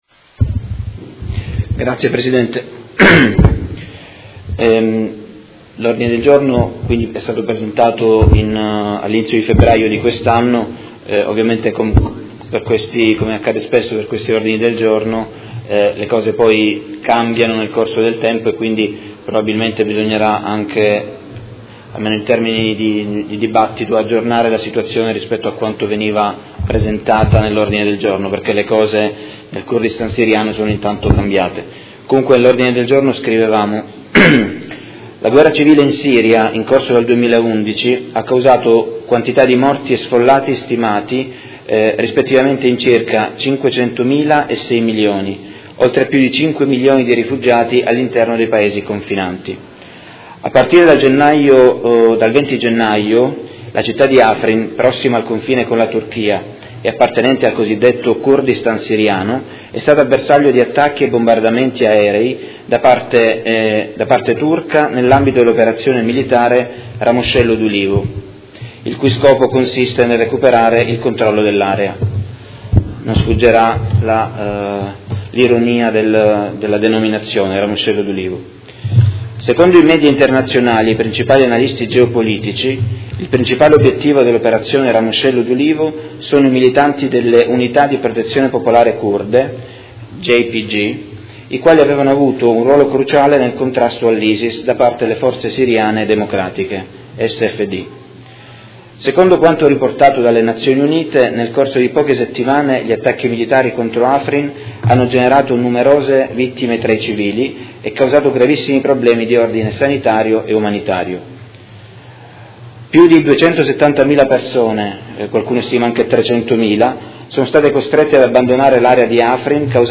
Tommaso Fasano — Sito Audio Consiglio Comunale